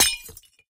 glass2.ogg